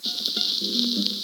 Sonido de un disco viejo
vinilo
Reproductor de discos (Pick-up)
Sonidos: Hogar